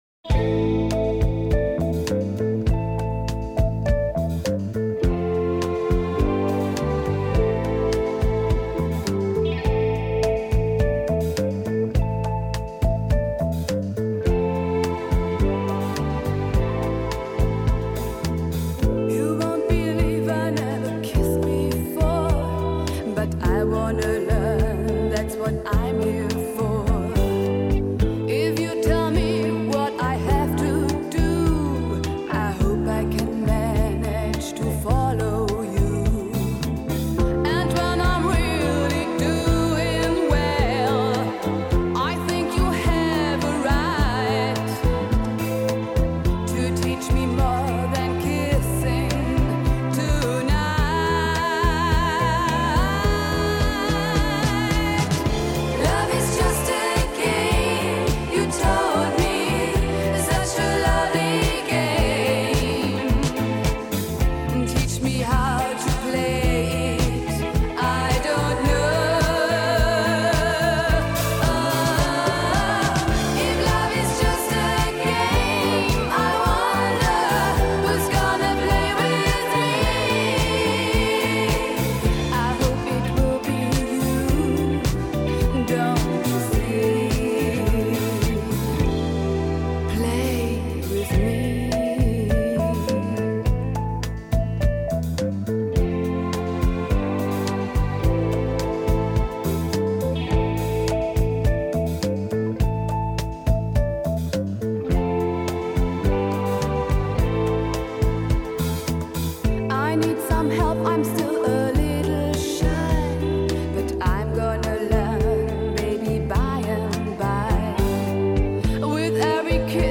Жанр:Диско